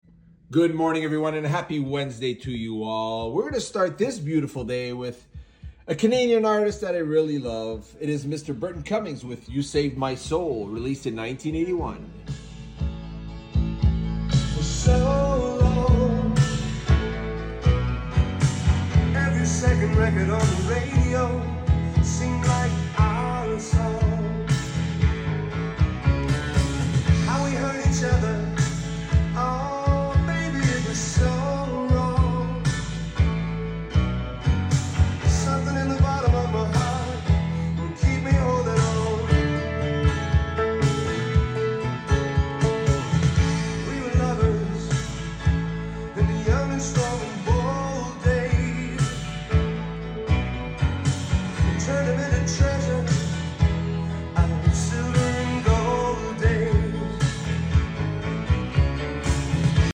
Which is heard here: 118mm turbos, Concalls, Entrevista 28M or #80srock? #80srock